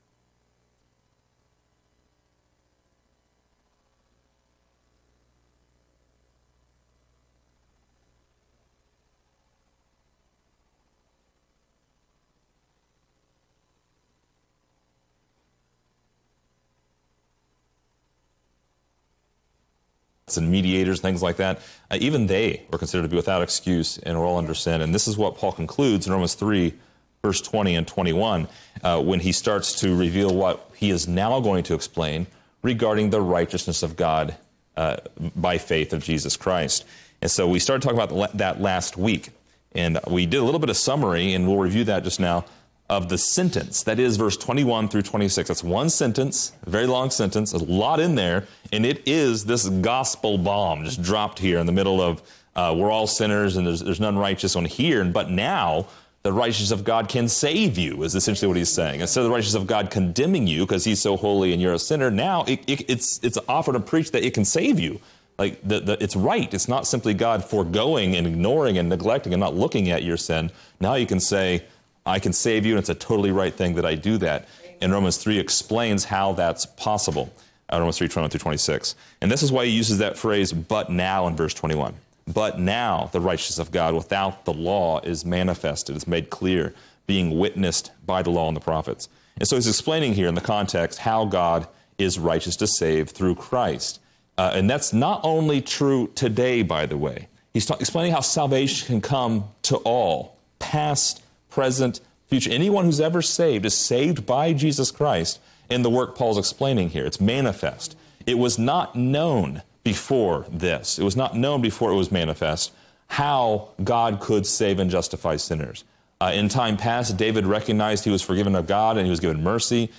Description: This lesson is part 23 in a verse by verse study through Romans titled: Faith in His Blood.